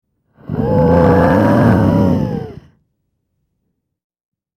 Alien Breathing; Large Creature Growl And Wheeze Breaths, Close Pov.